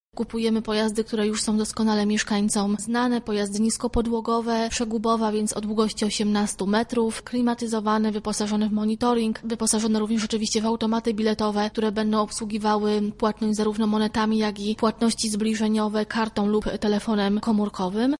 O tym, w co będą wyposażone pojazdy mówi